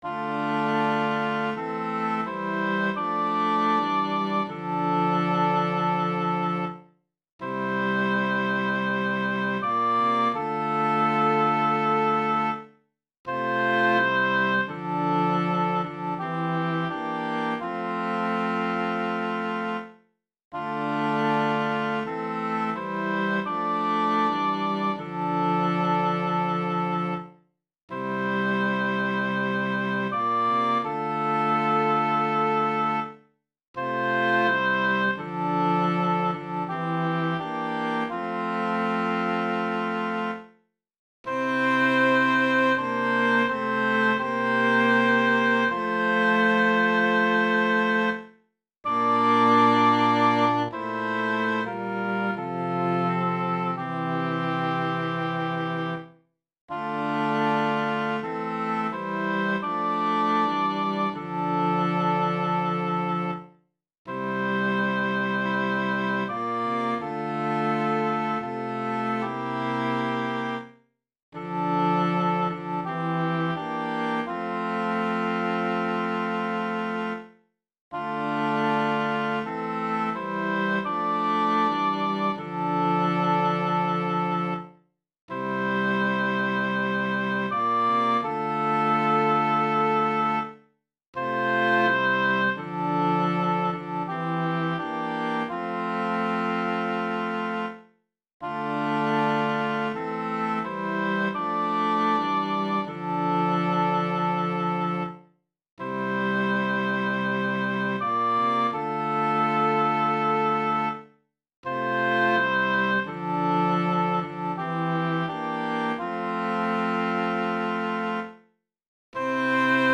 Voicing/Instrumentation: SATB